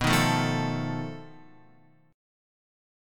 B7b5 Chord